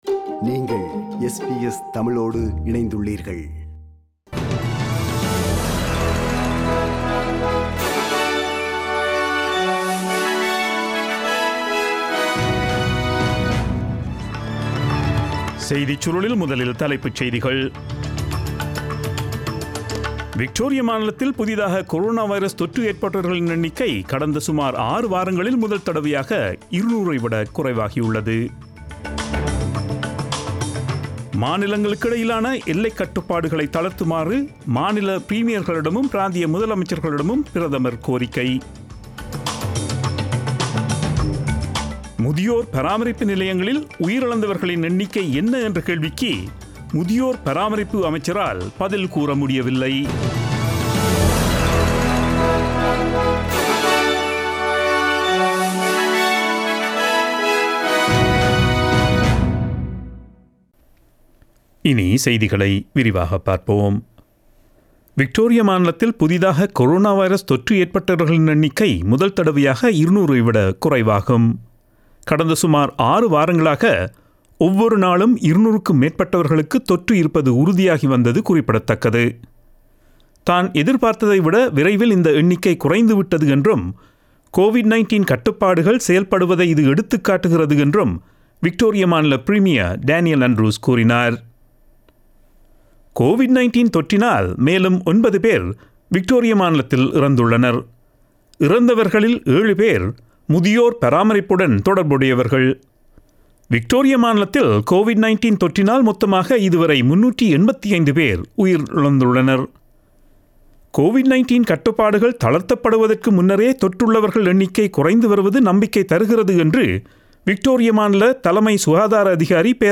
Australian news bulletin aired on Friday 21 August 2020 at 8pm.